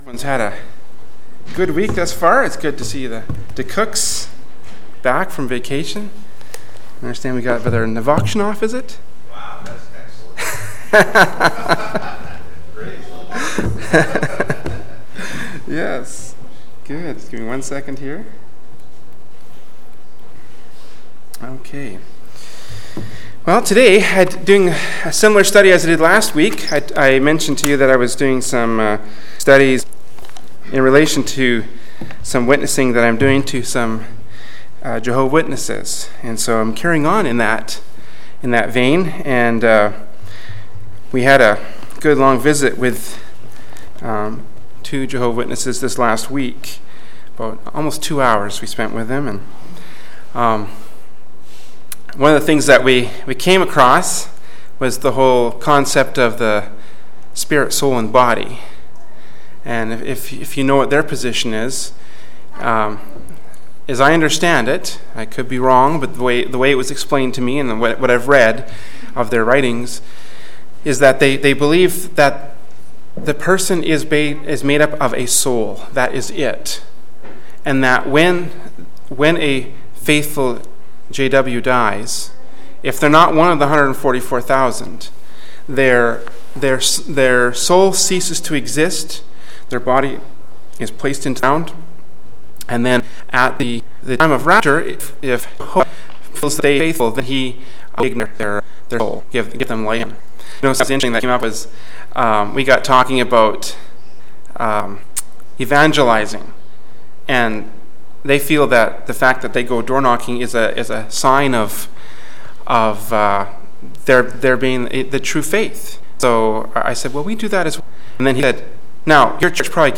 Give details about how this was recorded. Prayer Meeting